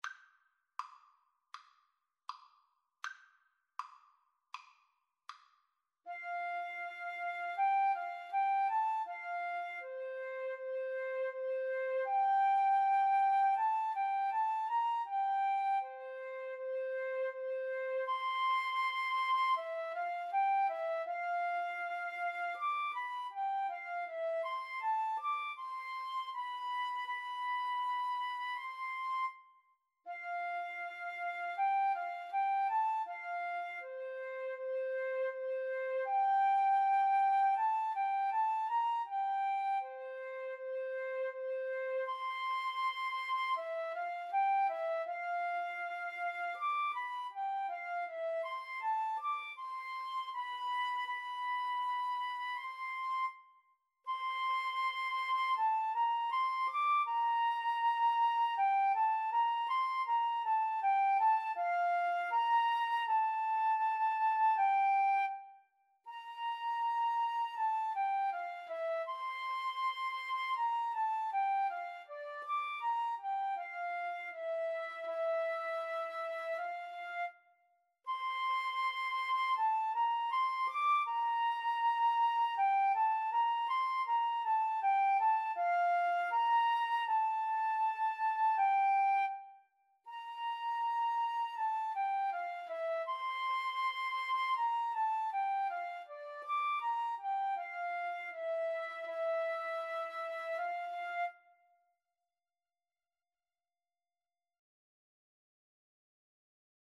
Flute Duet  (View more Easy Flute Duet Music)
Classical (View more Classical Flute Duet Music)